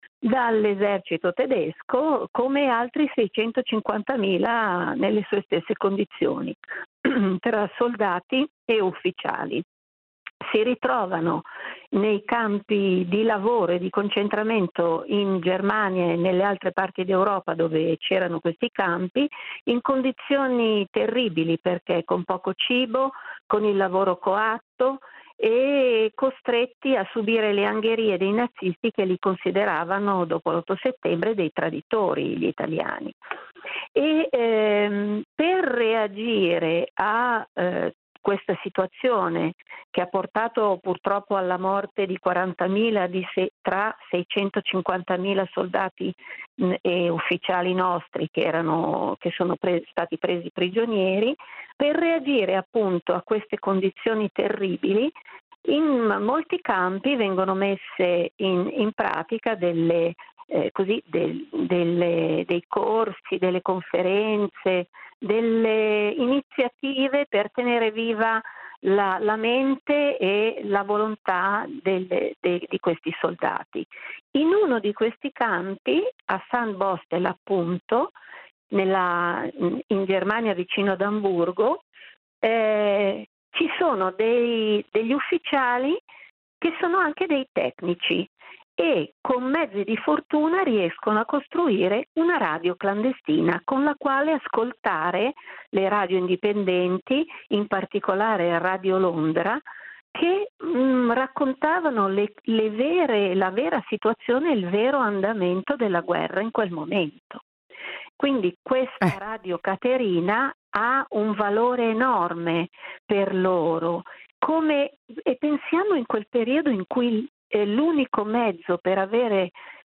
L'intervista